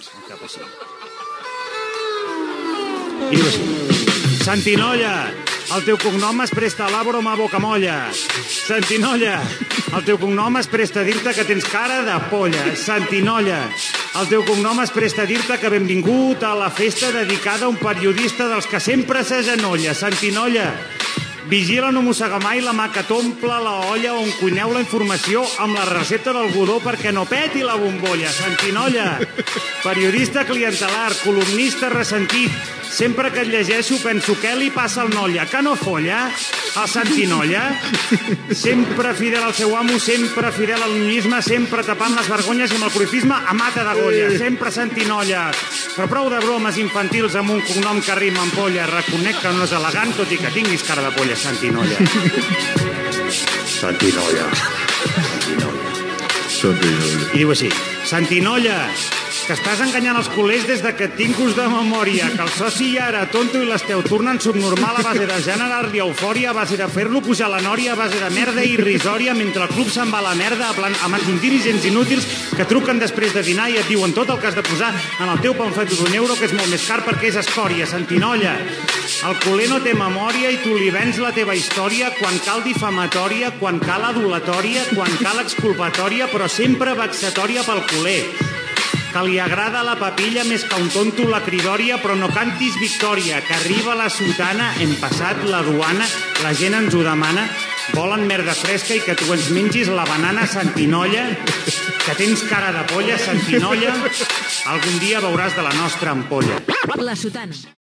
RAP humorístic i crític
Esportiu
FM